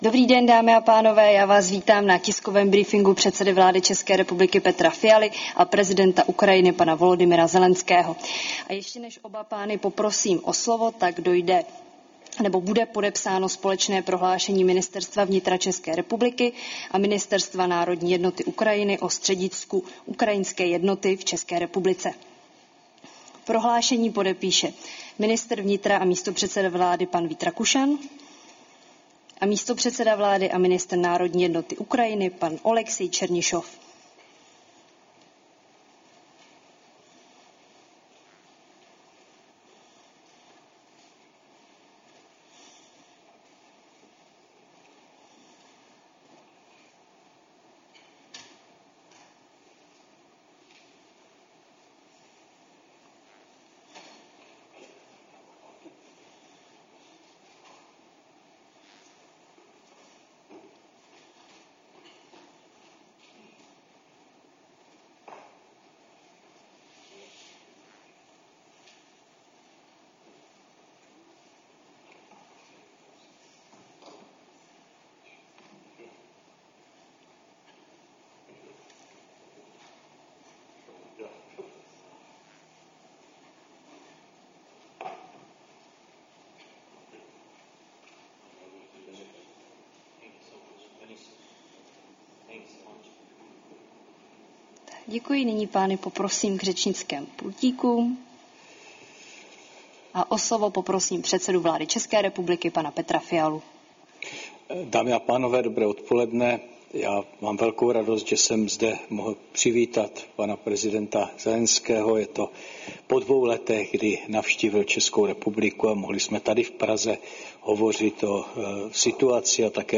Tiskový briefing premiéra Petra Fialy a prezidenta Ukrajiny Volodymyra Zelenského